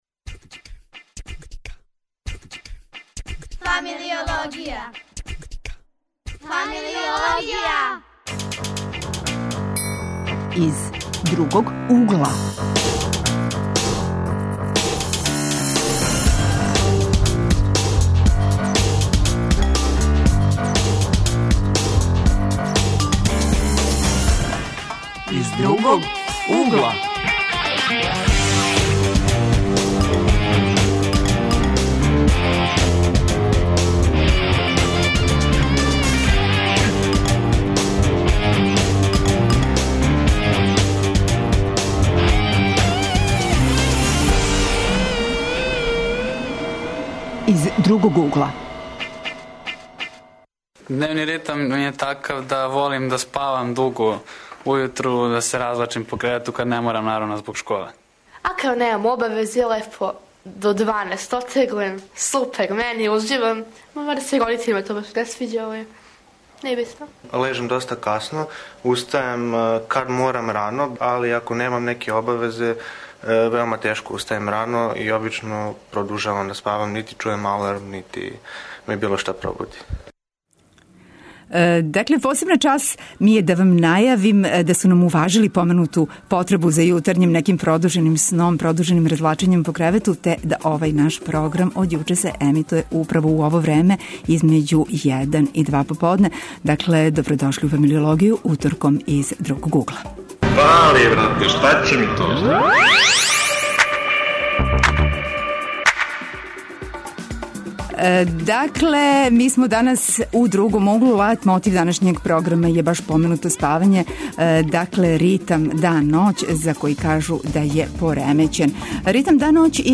У емисији 'Из другог угла' говоримо о поремећеном ритму спавања и устајања - зашто млади лежу касно и устају све касније? На ову тему говоре млади, психолози и родитељи.